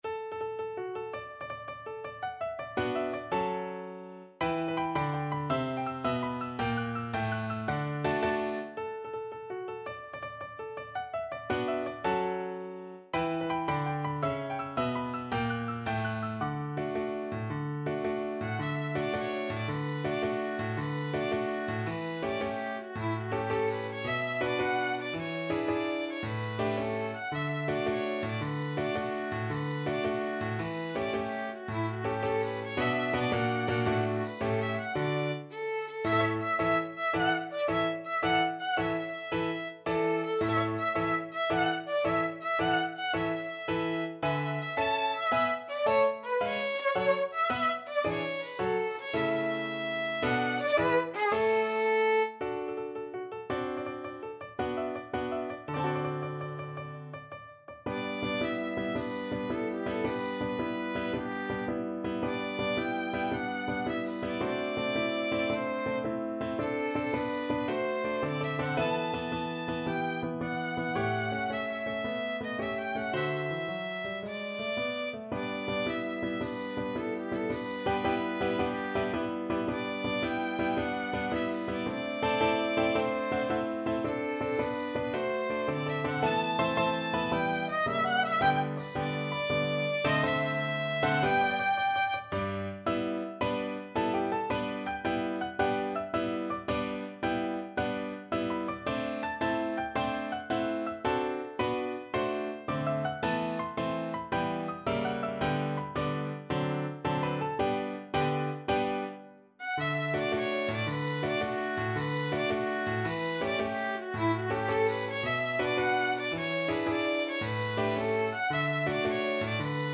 Violin
D major (Sounding Pitch) (View more D major Music for Violin )
6/8 (View more 6/8 Music)
March .=c.110
Classical (View more Classical Violin Music)